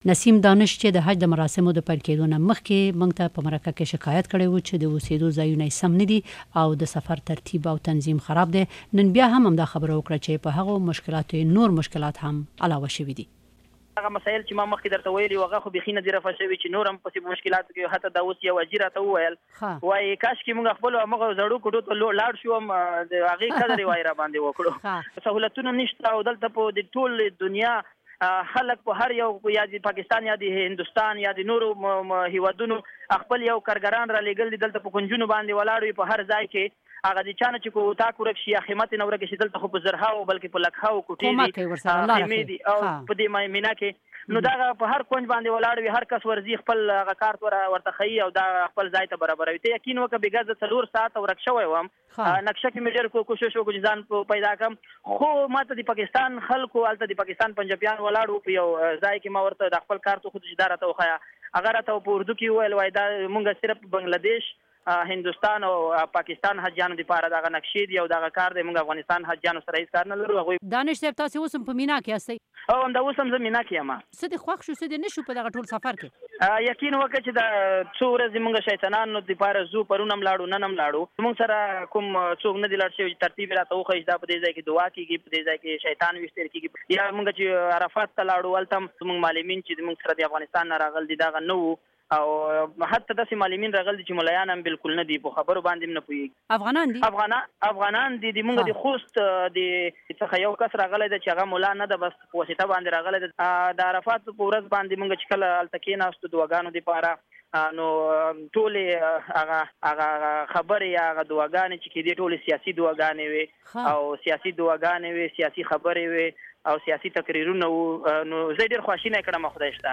دغه خبرې درېیو افغان حاجیانو کړې دي.